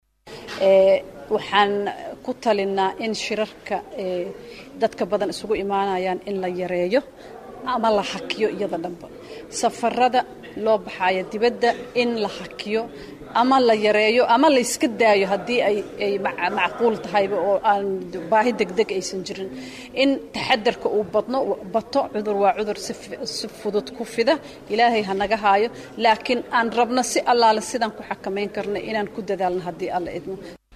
Halka hoose ka dhageyso codka Wasiirrada Caafimaadka: